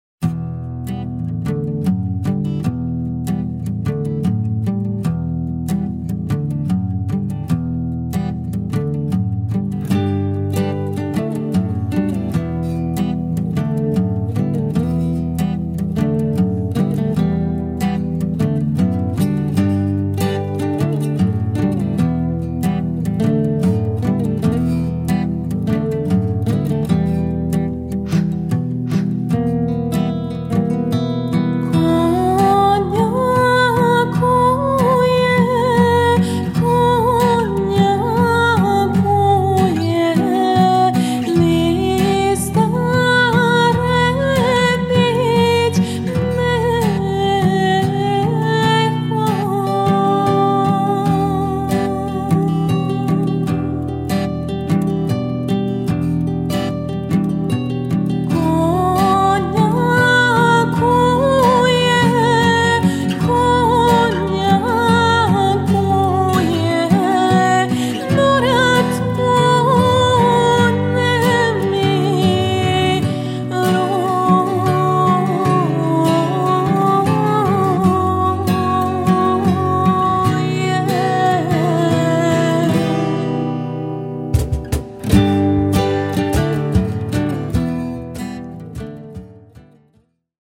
klasična kitara
akustična kitara
tolkala
violina
violončelo